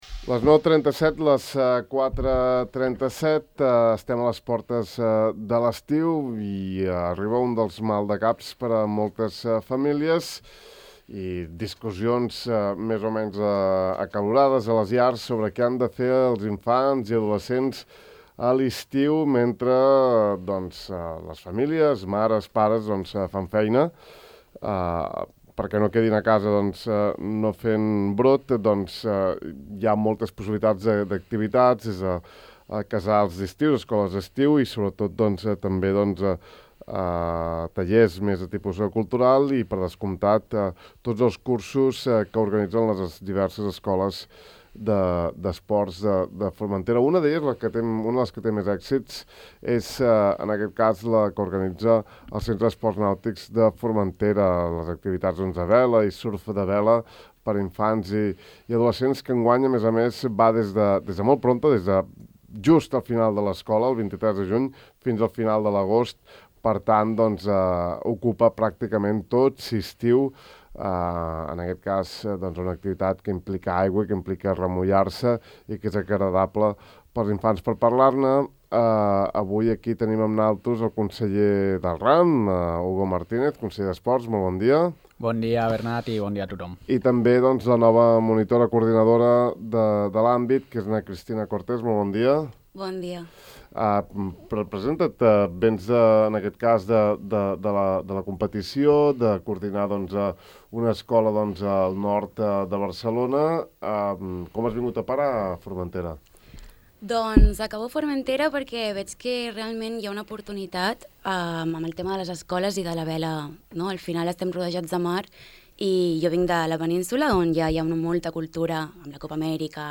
i el conseller insular d’Esports, Hugo Martínez, presenten a Ràdio Illa els cursos de vela lleugera i surf de vela per aquest estiu.